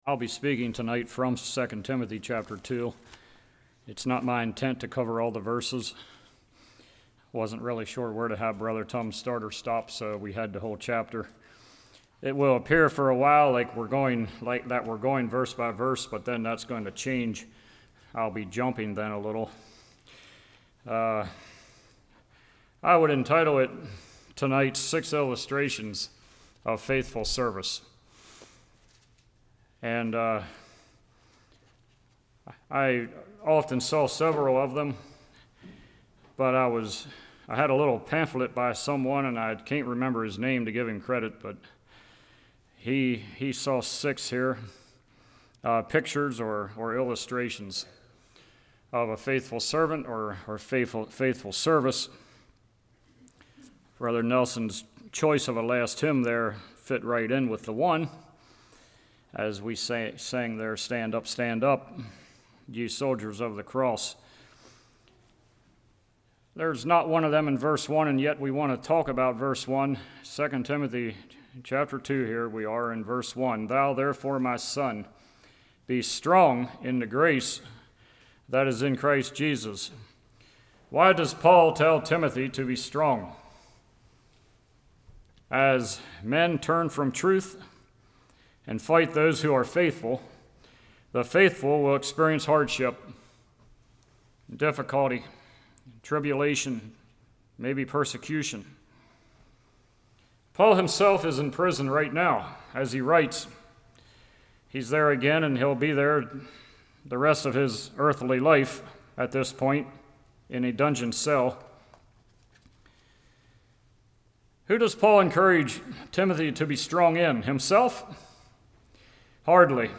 2 Timothy 2:1-26 Service Type: Evening Teacher Soldier Workman « A Revival that Requested Work Strife